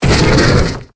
Cri de Gringolem dans Pokémon Épée et Bouclier.